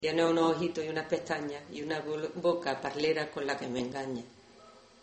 Materia / geográfico / evento: Canciones de mecedor Icono con lupa
Alhama de Granada Icono con lupa
Secciones - Biblioteca de Voces - Cultura oral